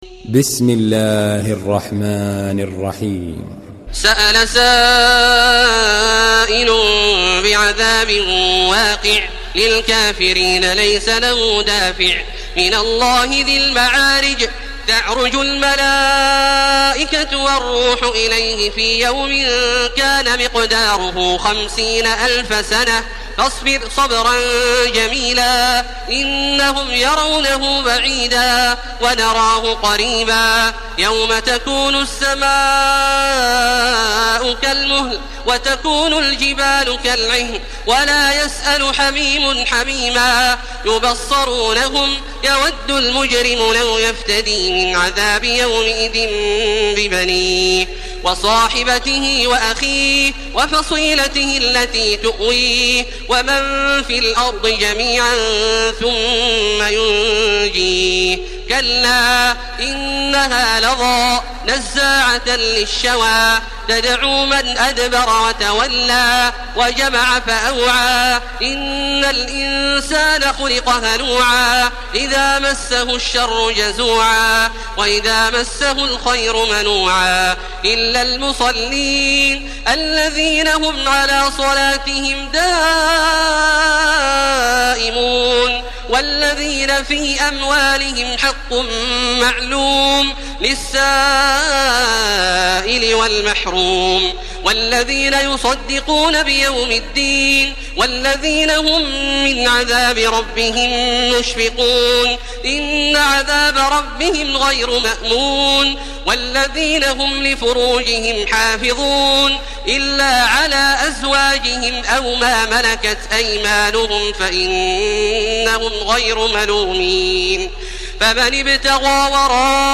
Surah Mearic MP3 by Makkah Taraweeh 1431 in Hafs An Asim narration.
Murattal